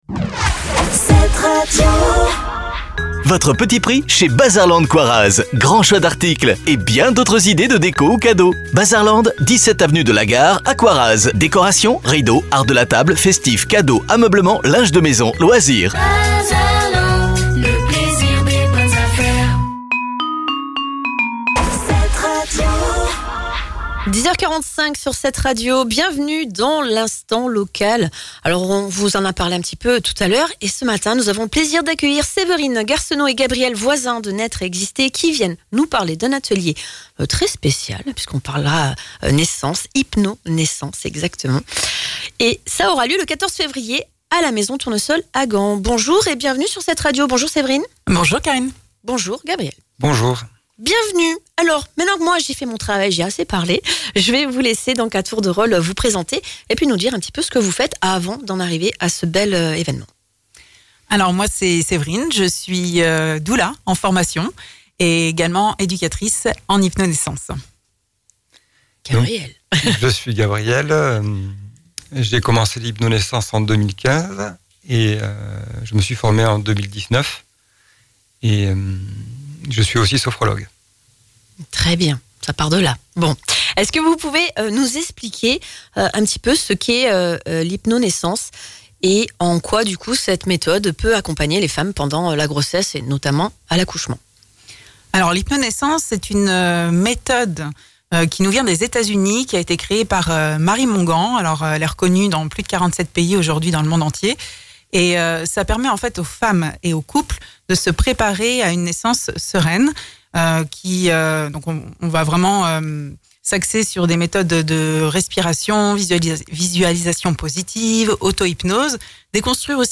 pour une interview autour d’un événement aussi doux qu’essentiel Ils sont venus nous présenter un atelier d’initiation à l’HypnoNaissance, destiné aux futurs parents souhaitant vivre une naissance la plus naturelle et respectée possible